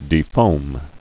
(dē-fōm)